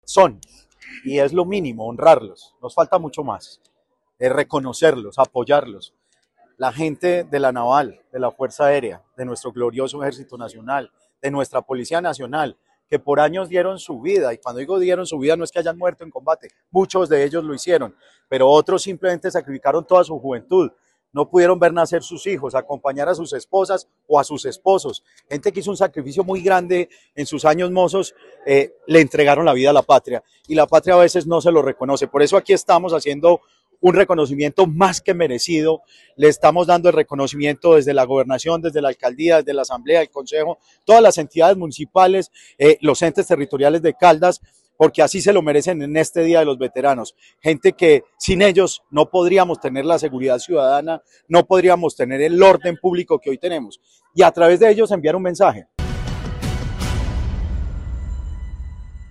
En compañía de los comandantes del Ejército y la Policía Nacional de la región, se desarrolló un acto conmemorativo del Día del Veterano de la Fuerza Pública en Caldas.
Jorge Eduardo Rojas Giraldo, Alcalde de Manizales.